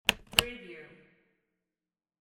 Briefcase Lock Wav Sound Effect #9
Description: The sound of a briefcase lock snapping open or close
Properties: 48.000 kHz 16-bit Stereo
Keywords: briefcase, open, opening, close, closing, latch, unlatch, business, case, lock, locking, unlock, unlocking, snap, click
briefcase-lock-preview-9.mp3